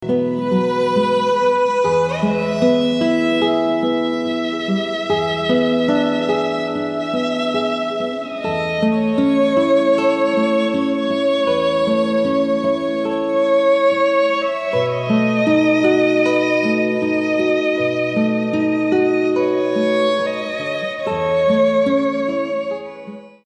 Songs for devotions, Violin with Guitar